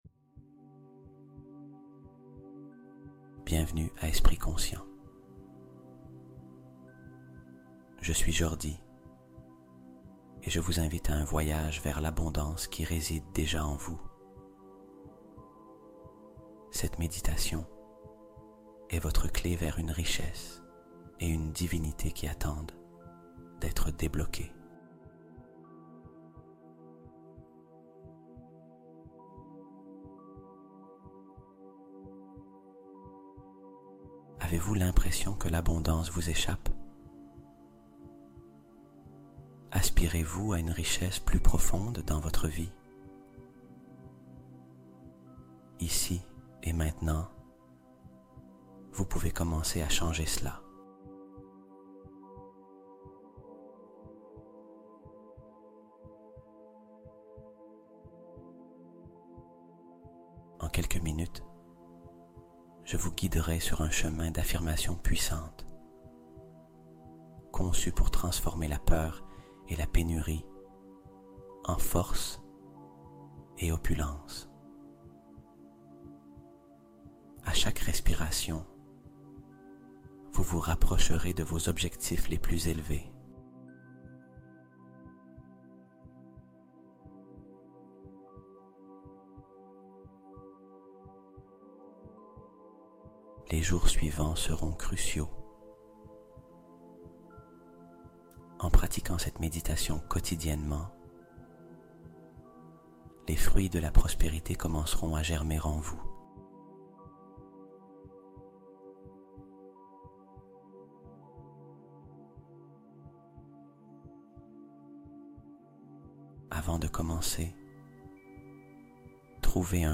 Nettoyage Mental : Effacer les anciens schémas par l'hypnose nocturne